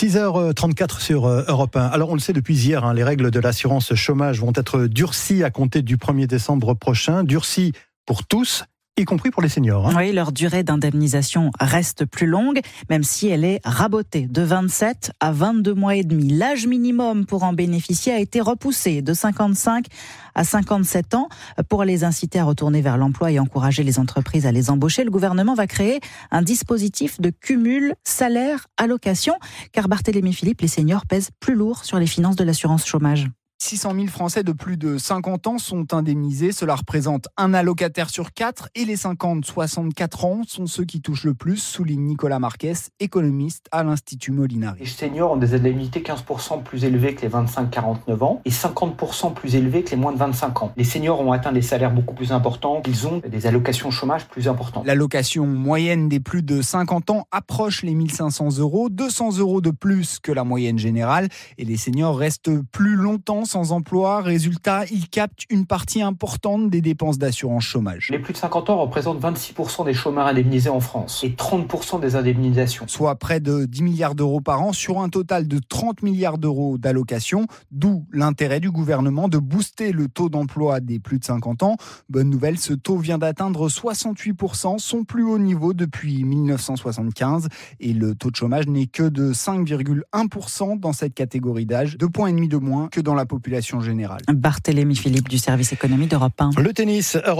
Europe 1, Bonjour – 5h-7h, 28 mai 2024